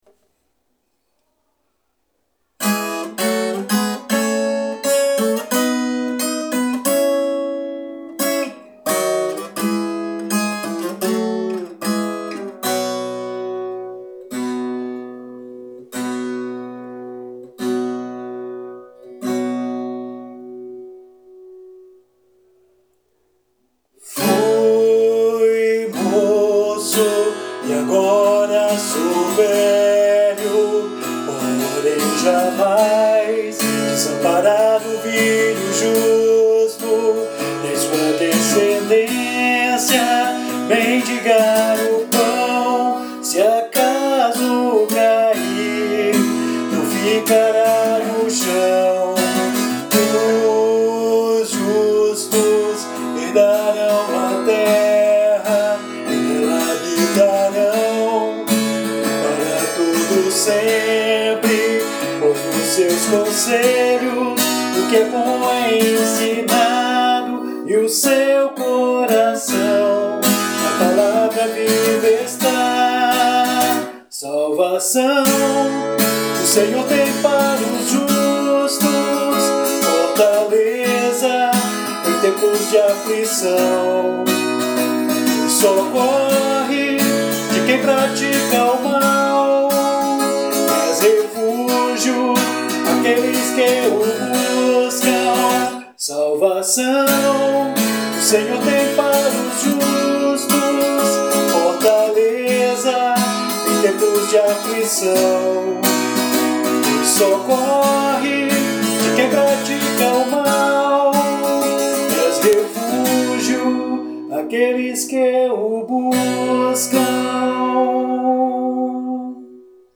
EstiloCountry